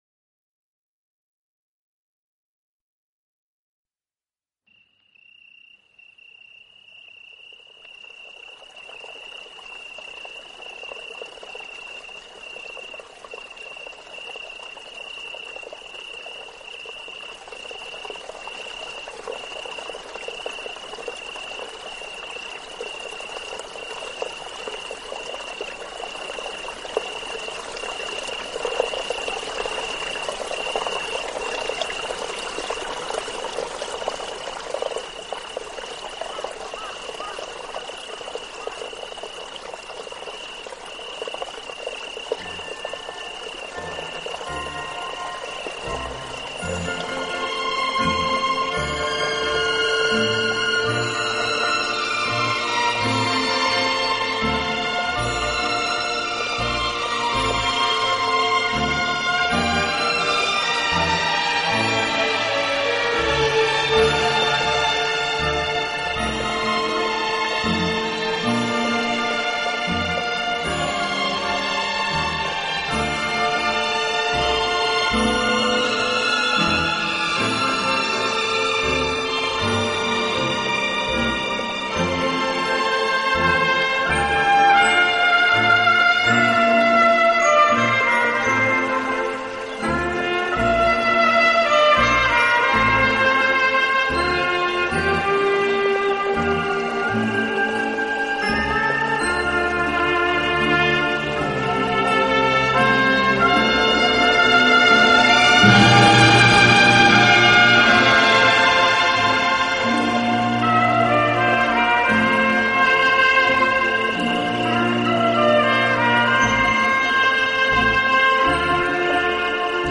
自然聲響與音樂的完美對話
流水、雀鳥之聲，能鎮靜人的情緒，鬆弛我們的身心，而且給人
海浪、流水、鳥鳴，風吹過樹葉，雨打在屋頂，
大自然的原始採樣加上改編的著名樂曲合成了天籟之音。